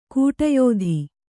♪ kūṭa yōdhi